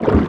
Sfx_creature_penguin_swim_05.ogg